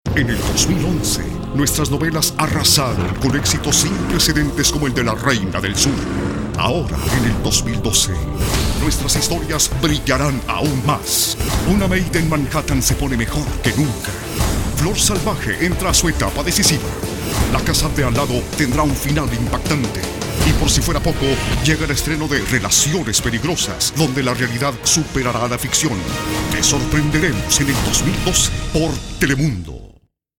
Spanish (neutral) speaker.
Sprechprobe: Werbung (Muttersprache):
My voice is warm, institutional